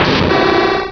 Cri de Démolosse dans Pokémon Rubis et Saphir.